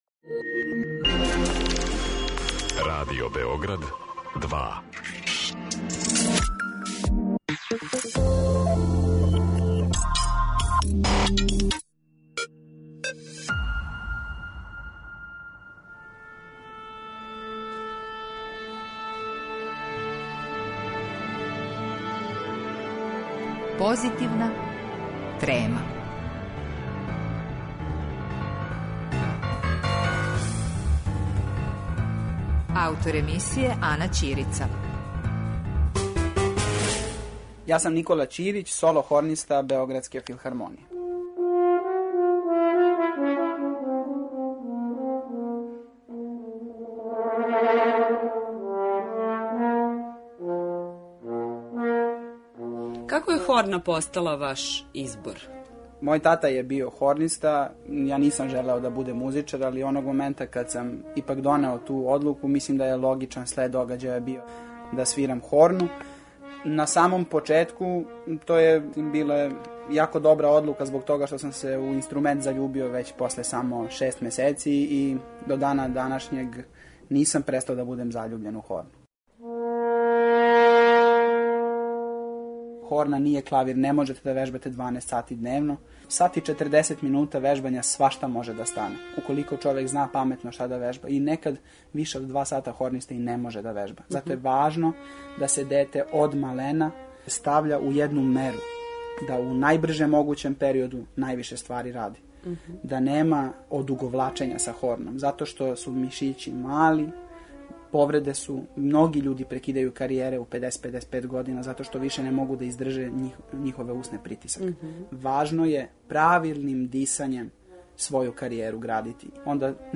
Разговор